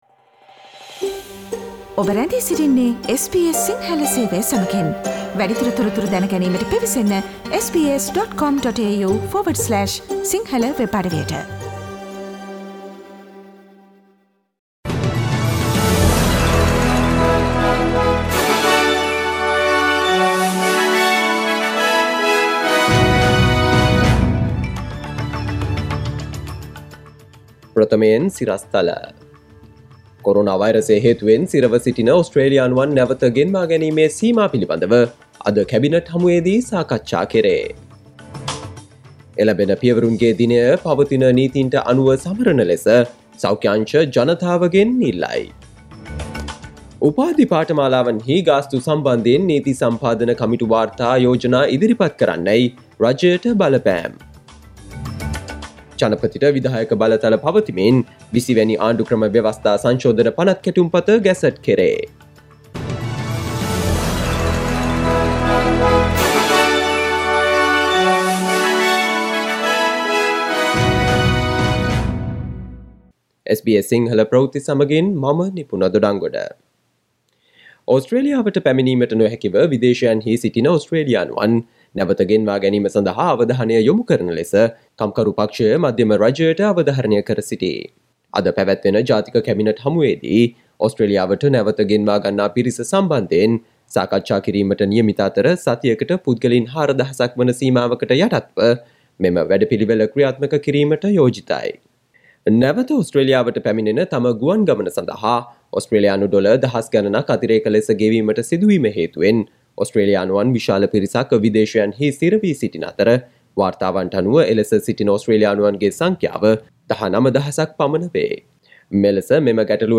Daily News bulletin of SBS Sinhala Service: Friday 04 September 2020